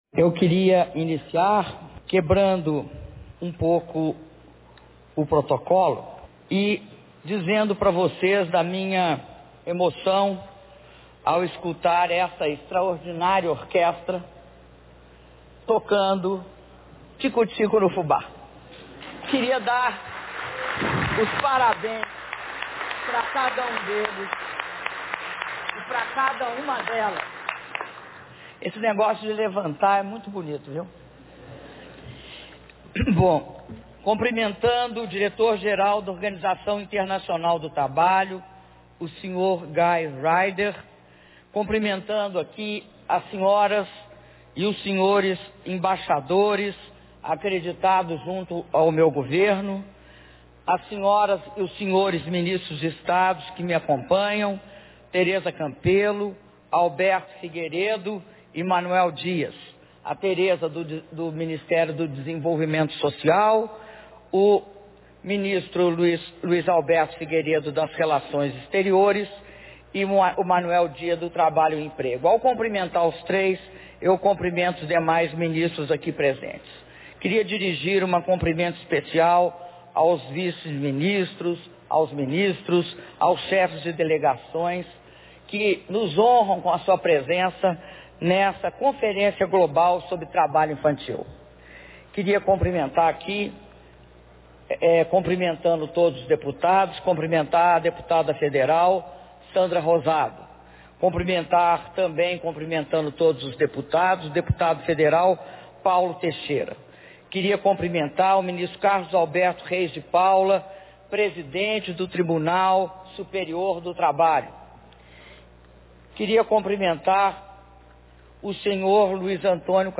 Discurso da Presidenta Dilma Rousseff na abertura da III Conferência Global sobre Trabalho Infantil - Brasília/DF